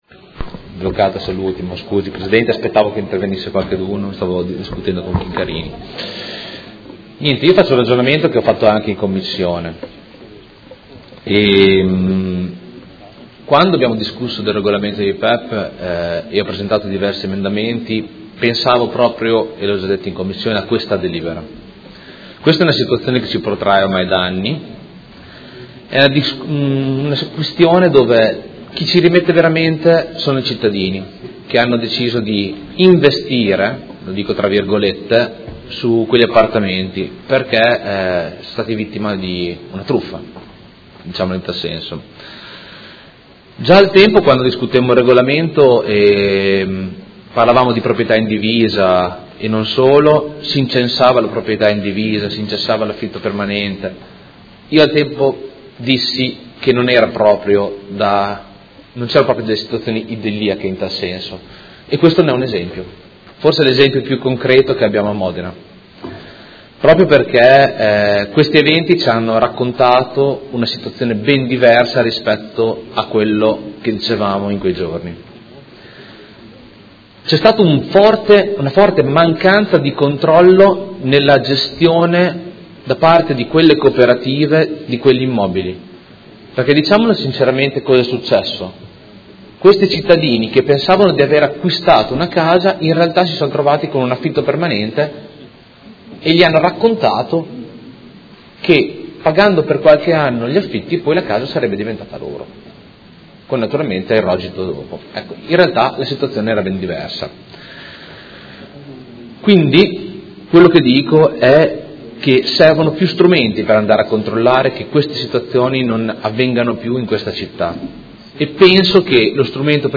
Luca Fantoni — Sito Audio Consiglio Comunale